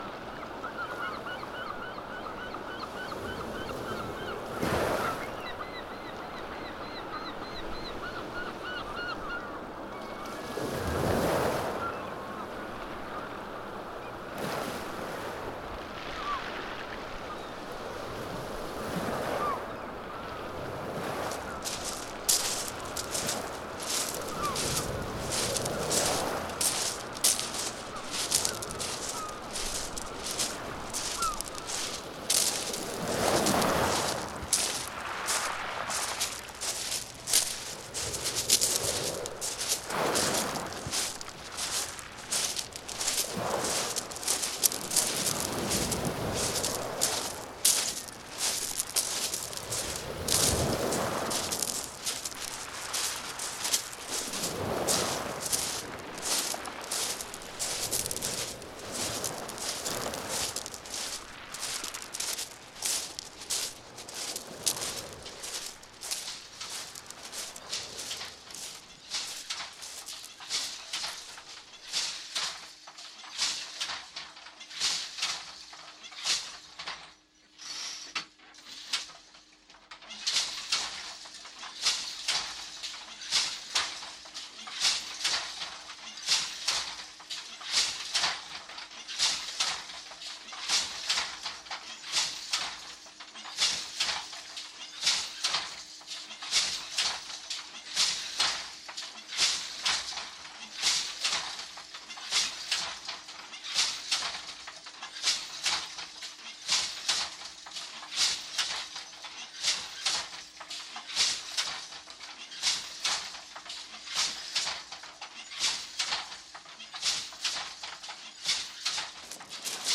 "unknown sounds" is intended as a radio archive of experimental artists’ interior worlds, these that are externalized through their work, and the interchange through artist communities.
Each section here is meant as a studio visit, but also a glimpse into the doings of a wider community and the cultural, political repercussions of experimental sound and art-making.